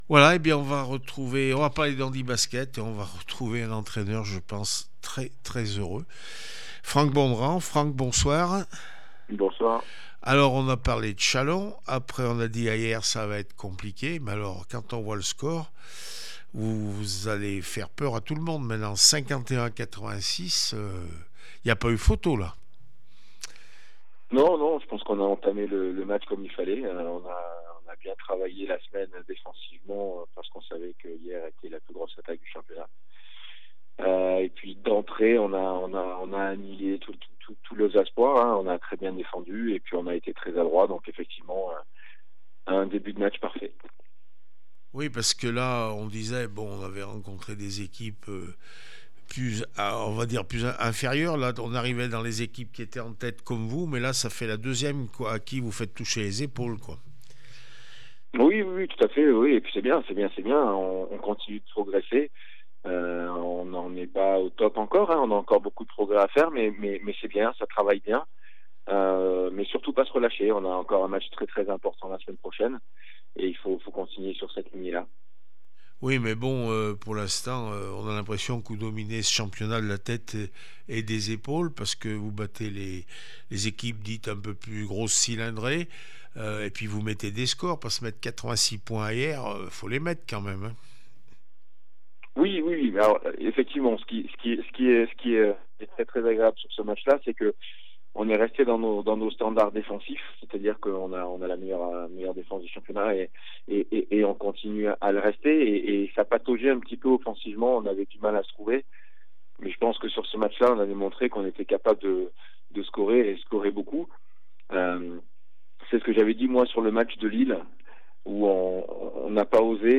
9 décembre 2024   1 - Sport, 1 - Vos interviews